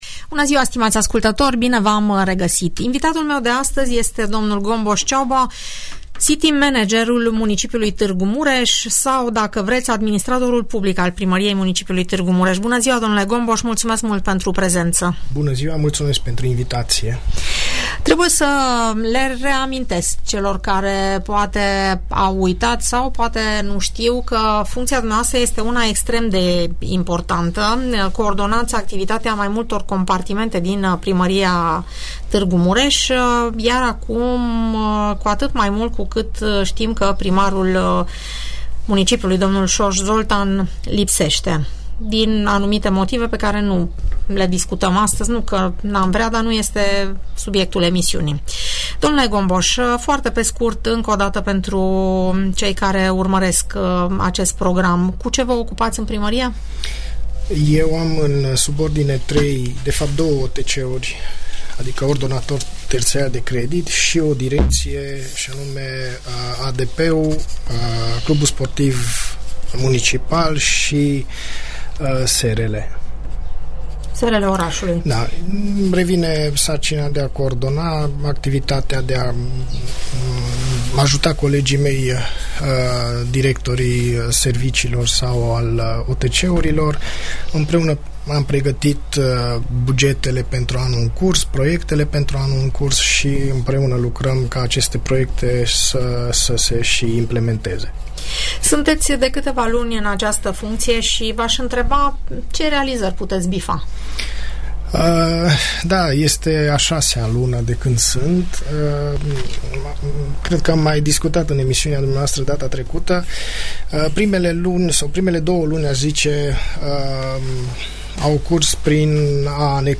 » Informații utile pentru târgumureșeni Informații utile pentru târgumureșeni Audiență radio cu administratorul public al Municipiului Tg. Mureș, dl Gombos Csaba, în emisiunea "Părerea ta" .